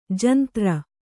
♪ jantra